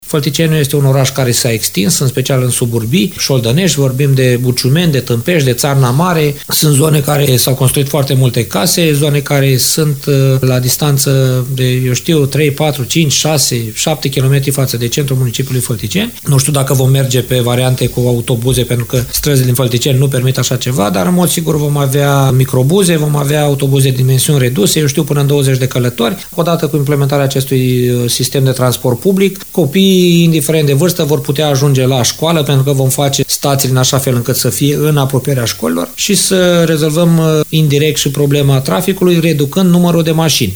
Primarul CĂTĂLIN COMAN a precizat, la Radio VIVA FM, că demersul este necesar datorită solicitărilor tot mai mari din partea locuitorilor din suburbii, unul din efecte fiind și reducerea traficului rutier cu autoturisme.